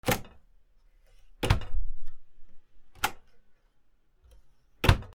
シンクの扉
『バタン』